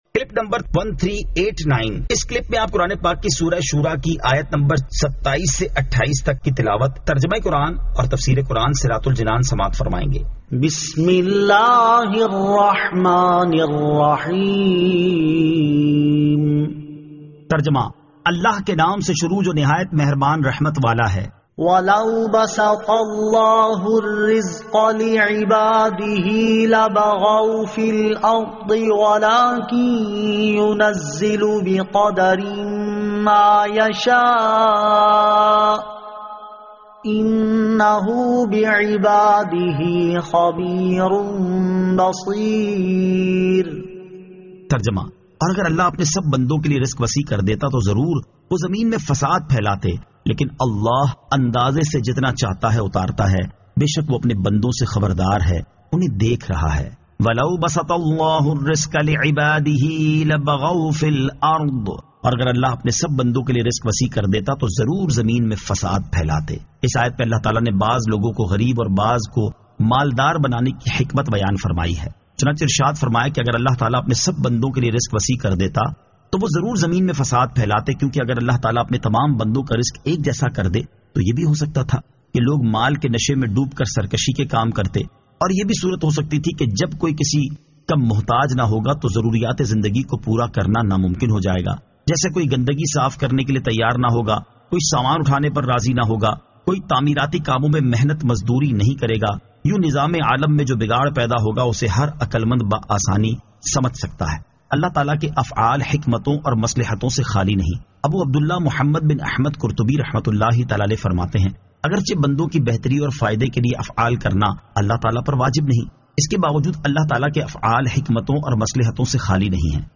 Surah Ash-Shuraa 27 To 28 Tilawat , Tarjama , Tafseer